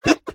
mob / witch / ambient5.ogg
ambient5.ogg